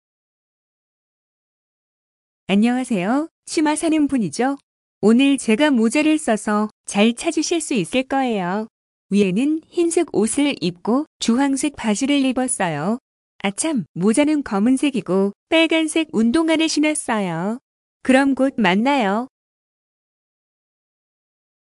The seller left you a voice message describing what she is wearing, so you can recognize her later.